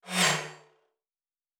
pgs/Assets/Audio/Sci-Fi Sounds/Movement/Fly By 02_1.wav at 7452e70b8c5ad2f7daae623e1a952eb18c9caab4
Fly By 02_1.wav